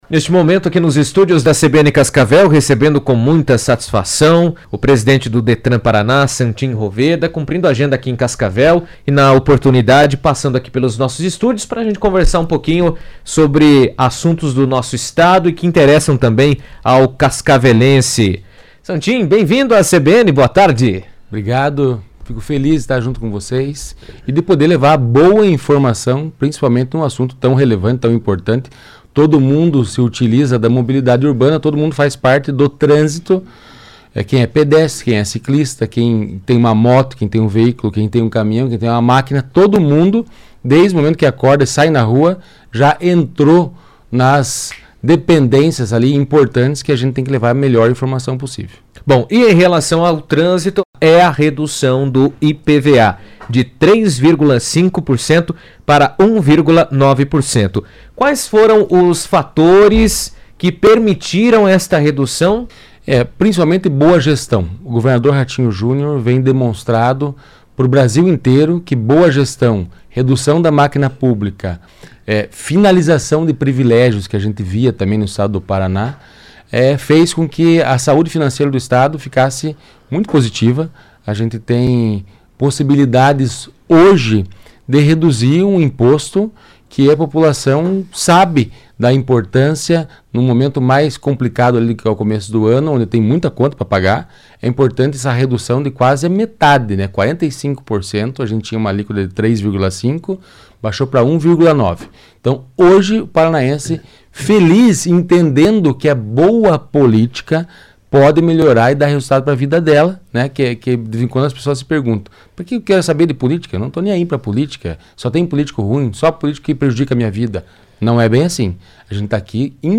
Com a redução de 45% no valor, o Paraná passa a ter o menor IPVA do Brasil, beneficiando motoristas de todo o estado. Em entrevista à CBN, Santin Roveda, presidente do Detran/PR, destacou que a medida representa um alívio financeiro para os contribuintes e reforça o compromisso do governo com políticas que incentivem a regularização e a circulação de veículos de forma segura.